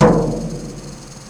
Instrument samples/percussion
Floor tom